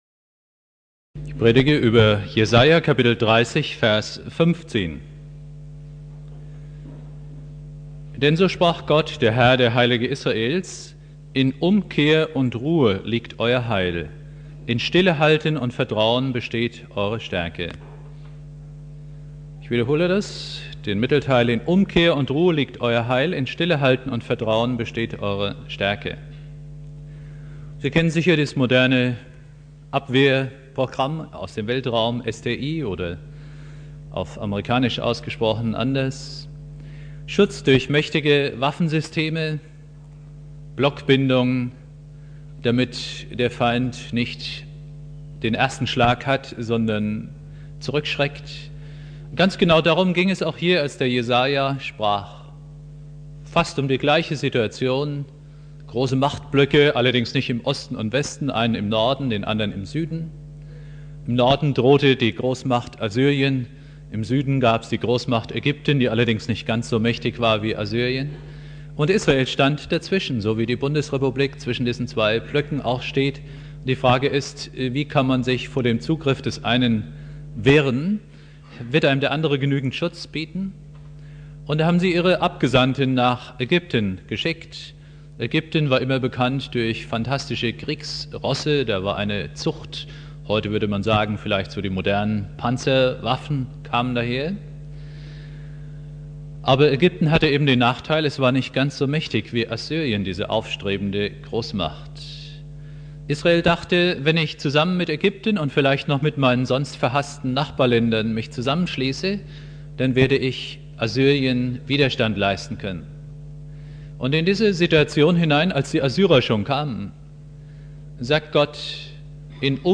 Predigt
Silvester Prediger